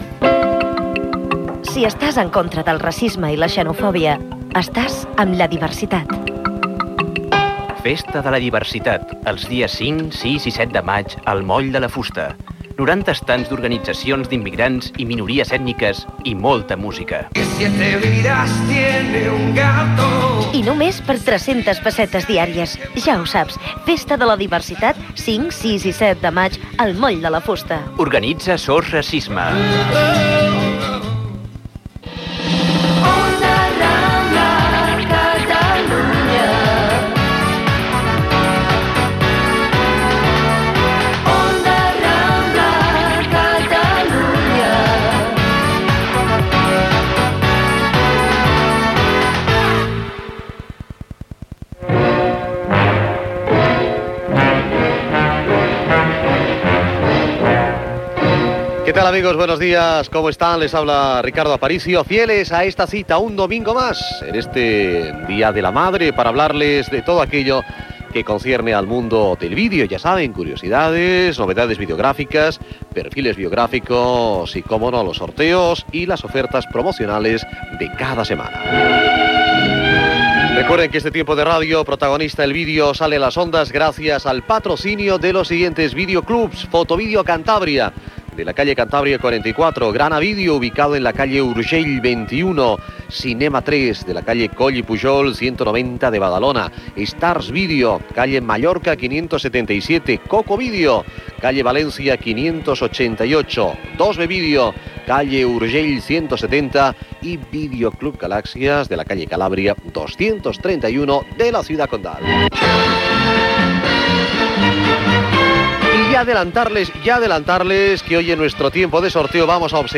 Gènere radiofònic Publicitat